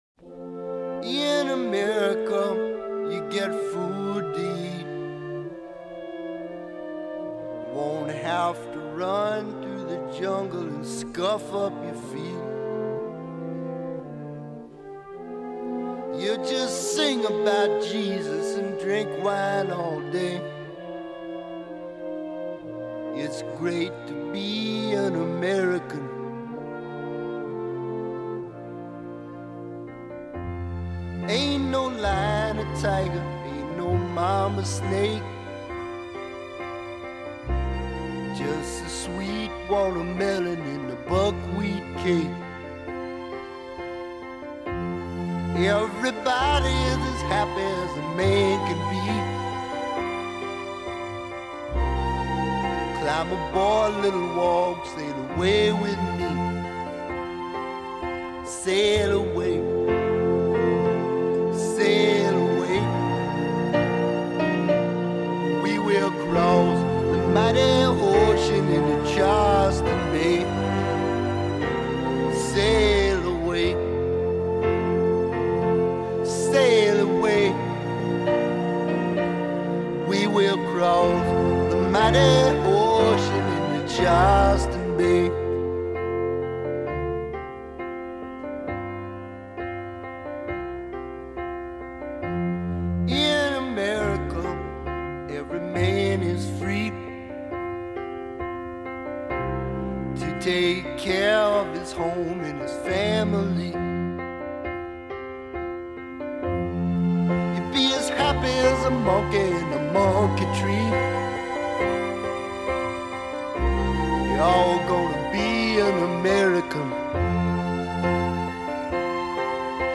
blues pop infused with the soul of New Orleans jazz
is a majestic invitation to America